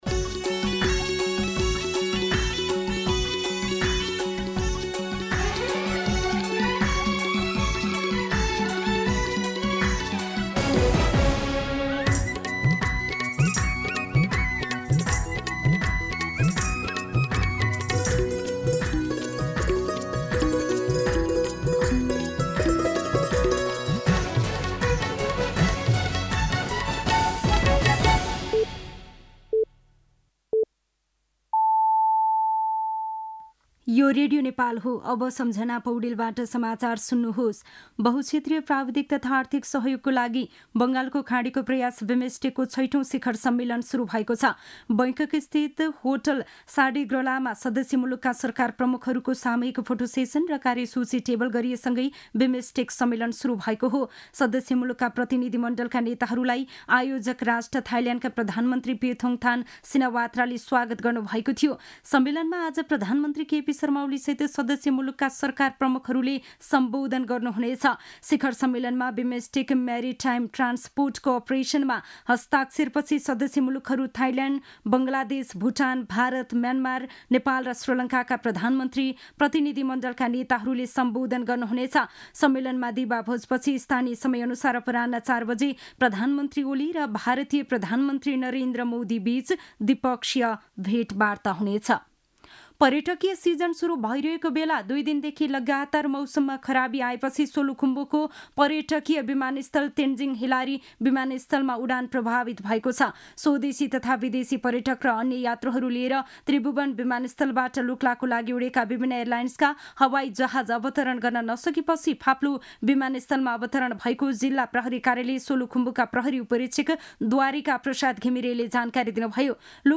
मध्यान्ह १२ बजेको नेपाली समाचार : २२ चैत , २०८१
12-pm-Nepali-News-1.mp3